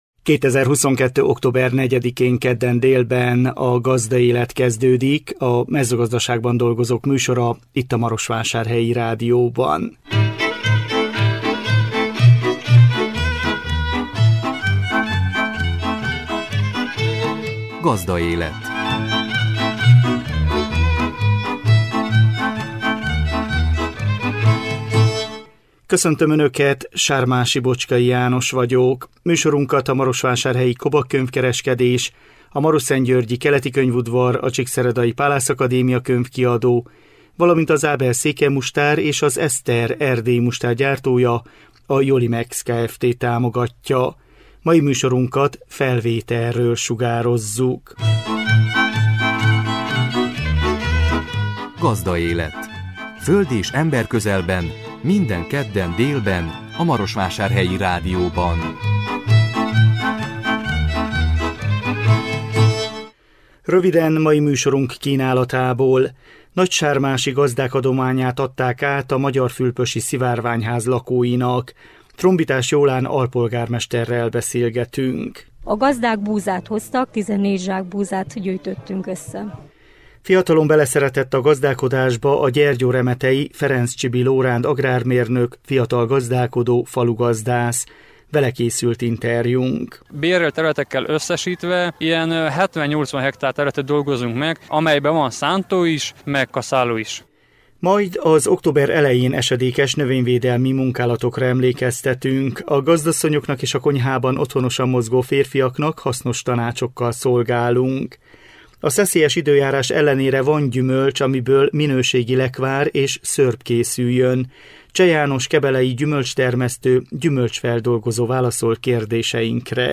Vele készült interjúnk. Majd az október elején esedékes növényvédelmi munkálatokra emlékeztetünk.